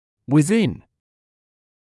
[wɪ’ðɪn][уи’зин]внутри; в пределах; в рамках